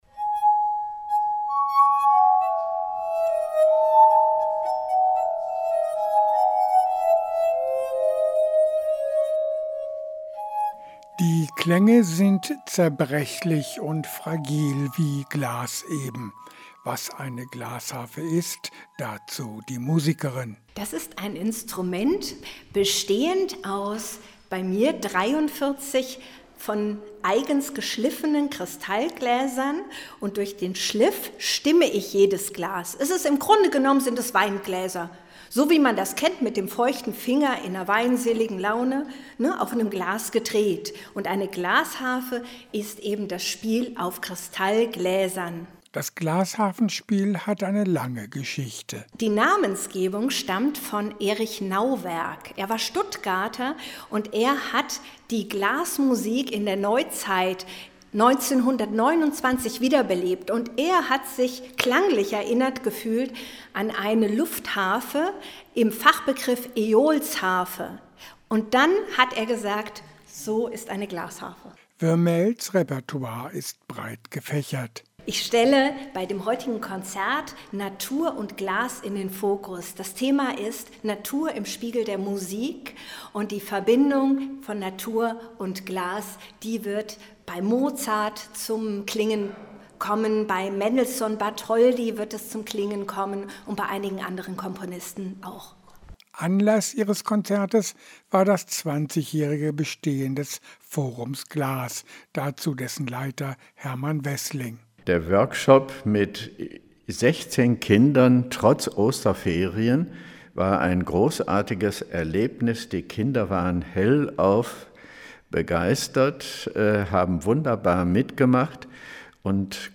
Bad Münder: Glasharfenkonzert
bad-muender-glasharfenkonzert-2.mp3